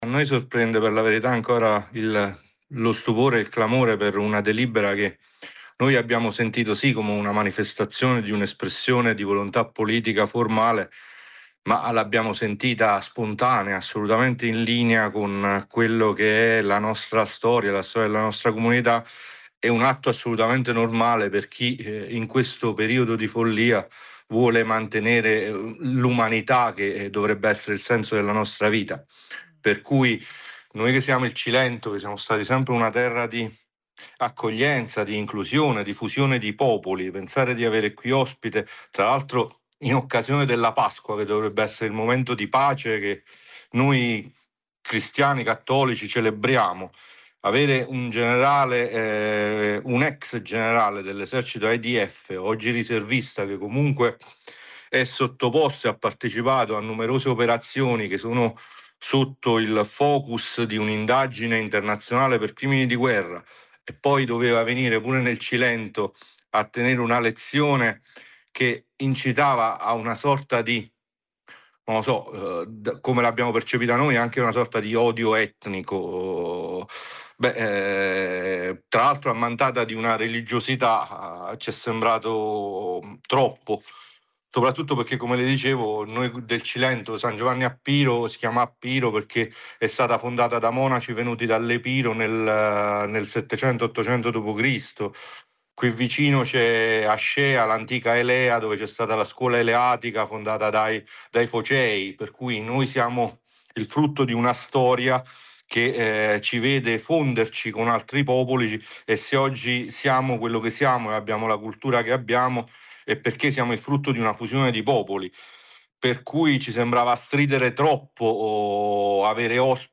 Un po’ troppo per il Comune di San Giovanni a Piro, che ha approvato una delibera per dire che non è gradita “la presenza del sig. Ofer Winter nel territorio cilentano”. Spiega il sindaco Ferdinando Palazzo: “Noi siamo frutto di una storia di migrazioni, fusioni di popoli, non possiamo accettare chi nega la diversità etnica e religiosa, la posizione del comune di San Giovanni a Piro è chiara sui principi di pace e tutela dei diritti umani.